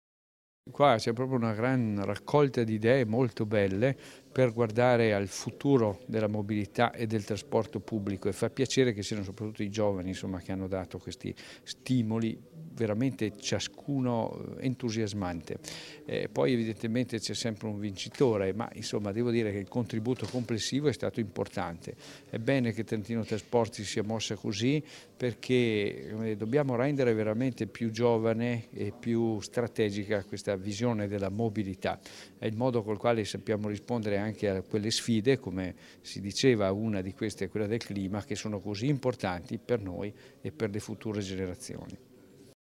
A Trentino Trasporti la premiazione del "Progetto pensiline"
Alla presenza degli studenti e dell'assessore Mauro Gilmozzi
l'assessore_Mauro_Gilmozzi.mp3